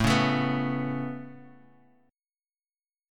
Aadd9 chord